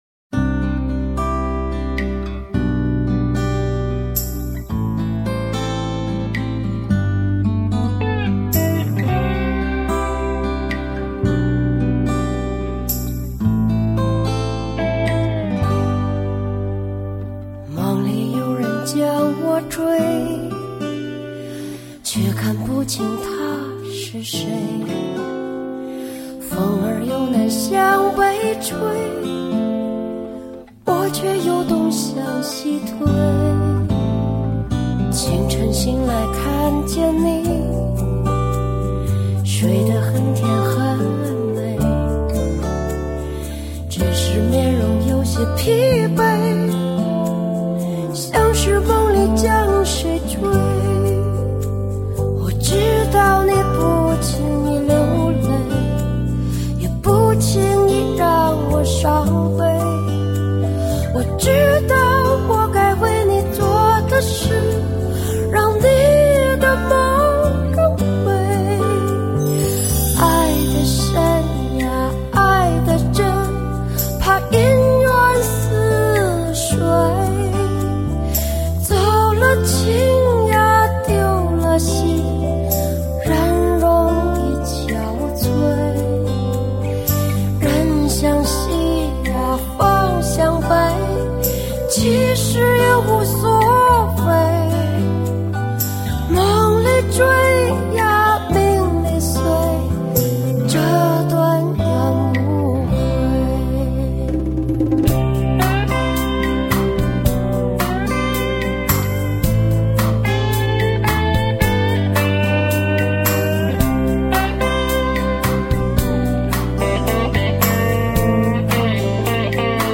全国流行歌坛“西北风”头号旗手，当今歌坛最独特的声音爆发十足唱腔魅力，深请吟唱现代人内心孤独的苦。